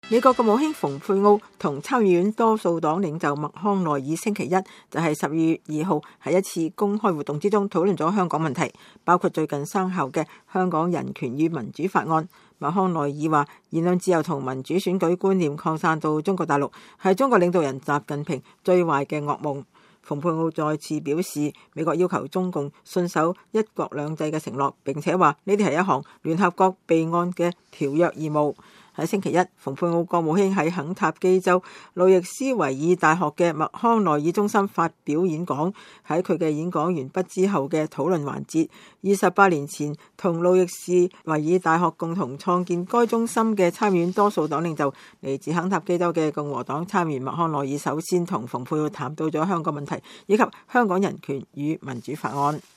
美國國務卿蓬佩奧和參議院多數黨領袖麥康奈爾星期一(12月2日)在一次公開活動中討論了香港問題，包括最近生效的《香港人權與民主法案》。麥康奈爾說，言論自由和民主選舉觀念擴散到中國大陸是中國領導人習近平“最壞的噩夢”。